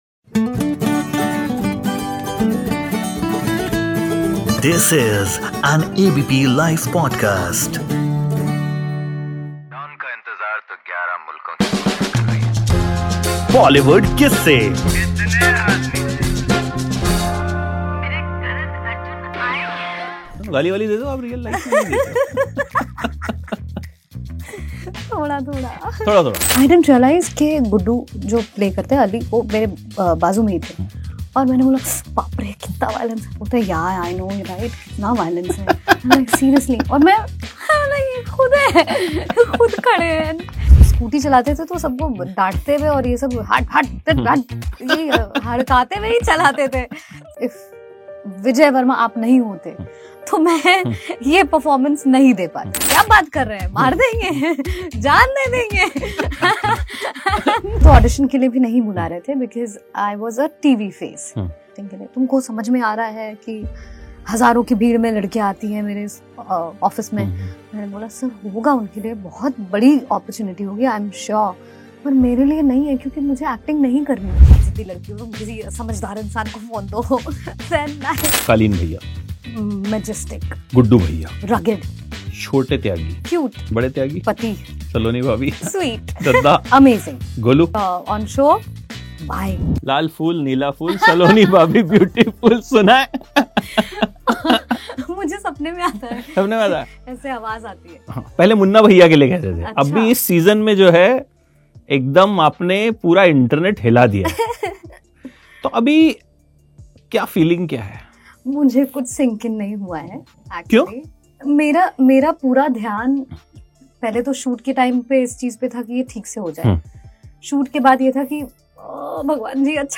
इंटरव्यू में उन्होंने हमें शूटिंग के दौरान हुई कई गतिविधियों के बारे में बताया और साथ ही अपने बारे में भी कुछ बातें बताईं।